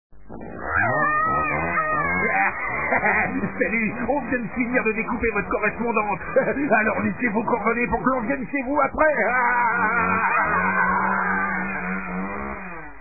Messages Comiques Repondeurs